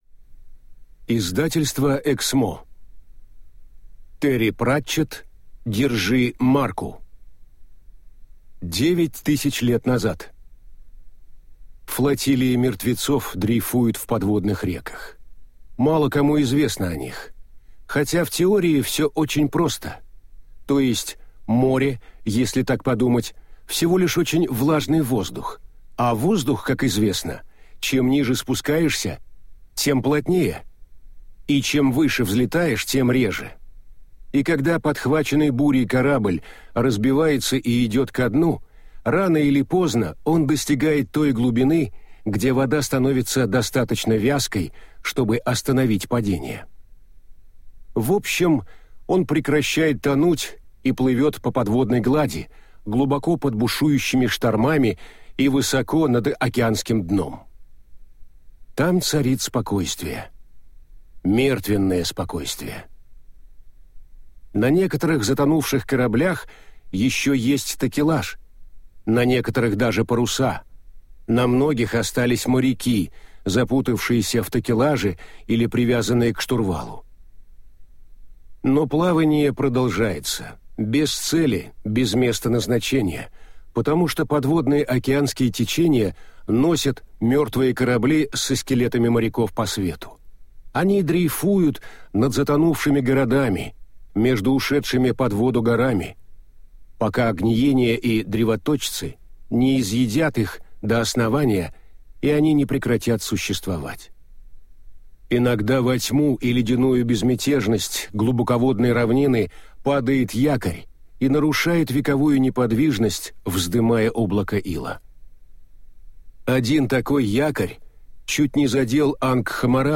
Aудиокнига Держи марку!
Прослушать и бесплатно скачать фрагмент аудиокниги